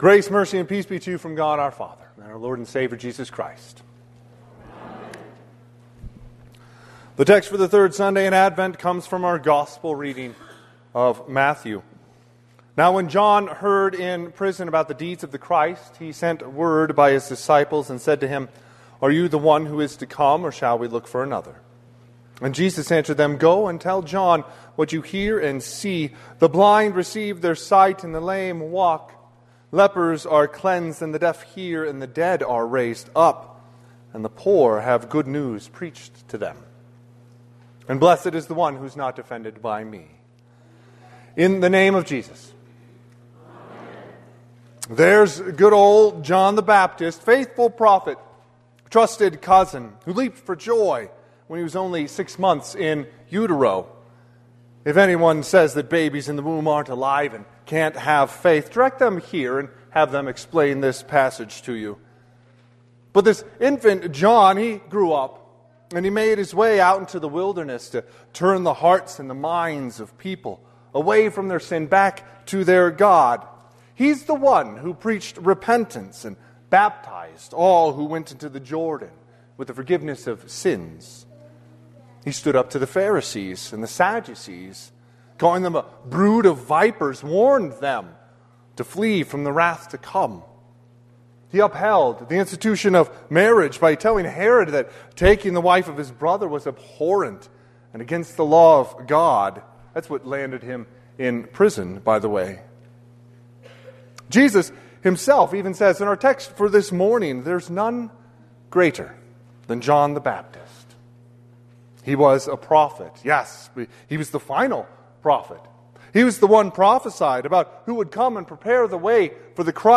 Sermon - 12/14/2025 - Wheat Ridge Evangelical Lutheran Church, Wheat Ridge, Colorado